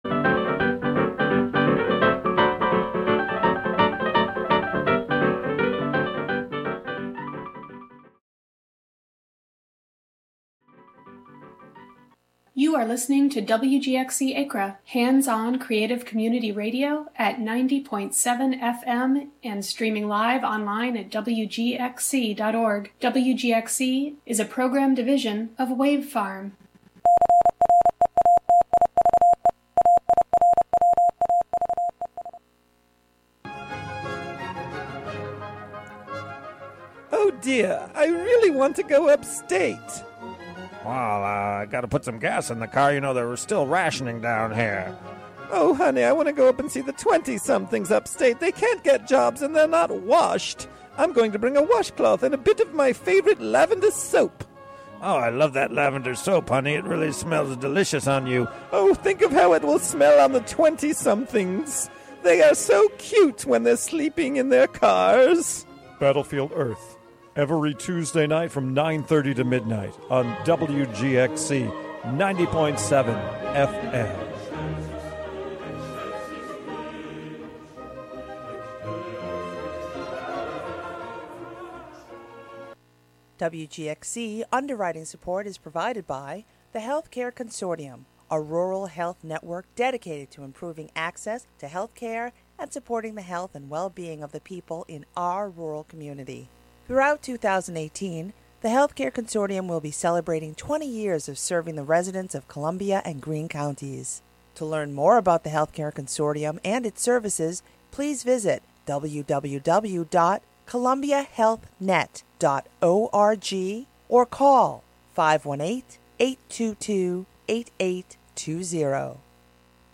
Featuring interviews and discussion on a wide range of local issues, culture, and politics.
Broadcast live from the WGXC Hudson studio.